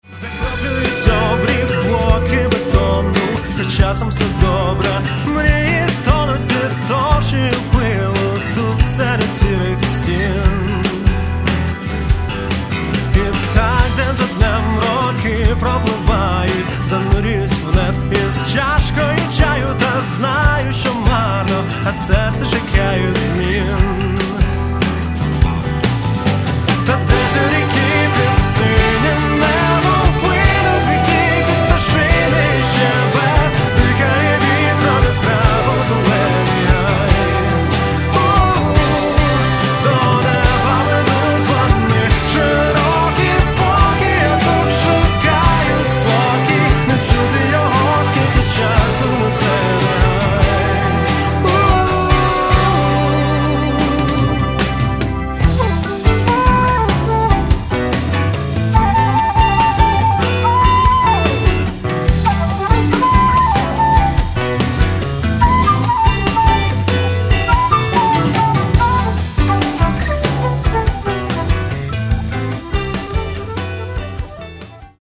Каталог -> Рок и альтернатива -> Поэтический рок